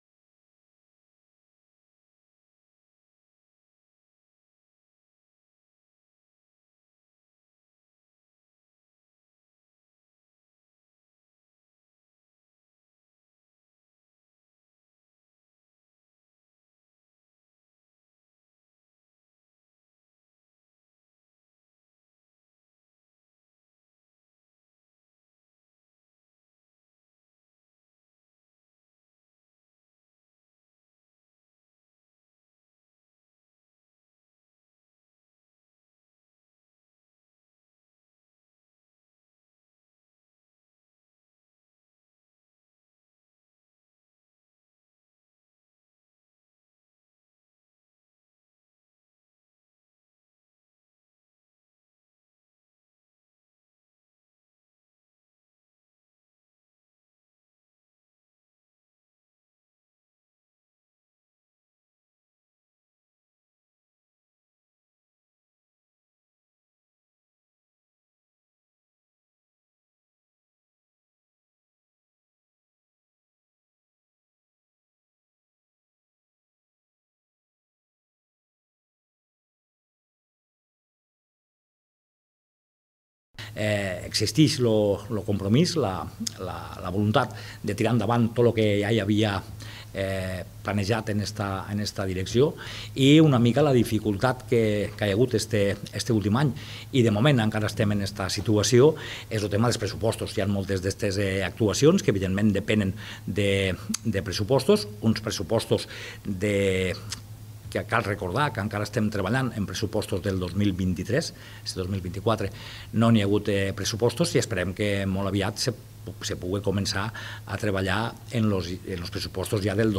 Tall-de-veu-Gonell.mp3